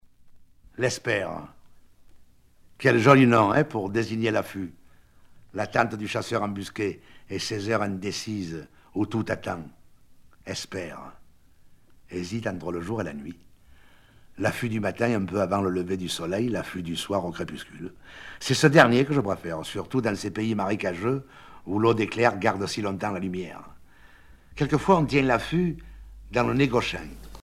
Genre récit